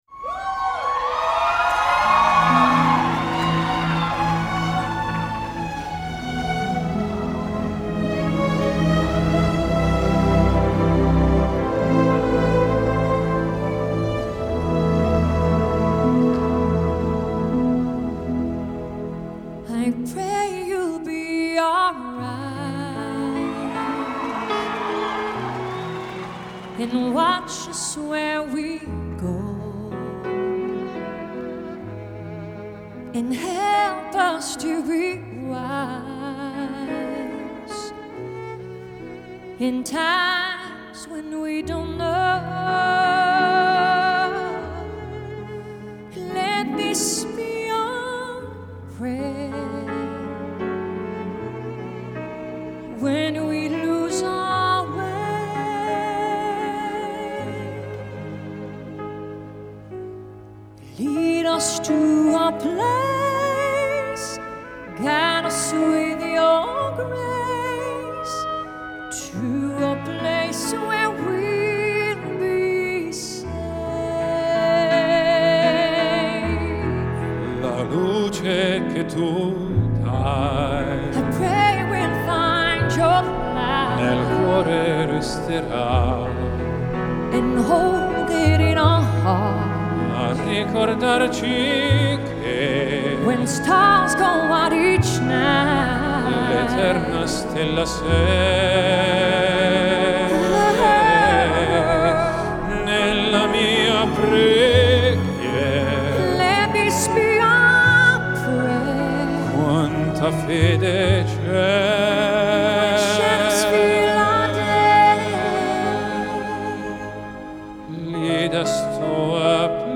Genre : Classical
Live At Central Park, New York